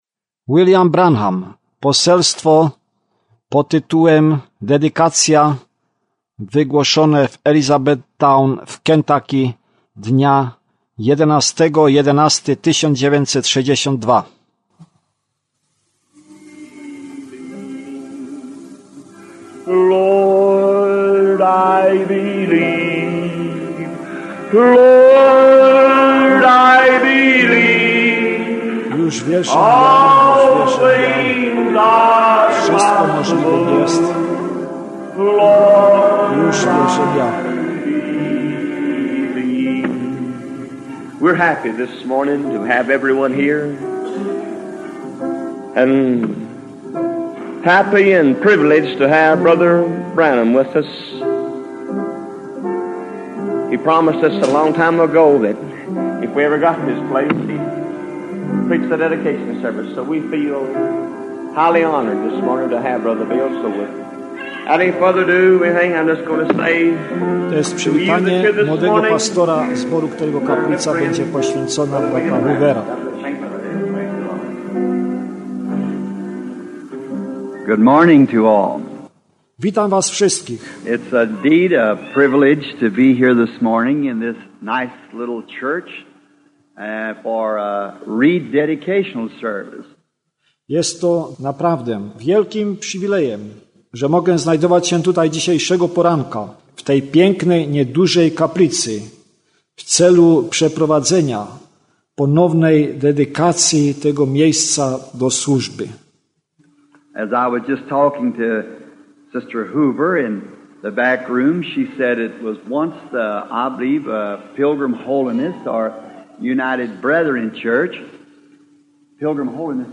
Kazanie "Dedykacja" (Dedication) — Elizabeth-town KY.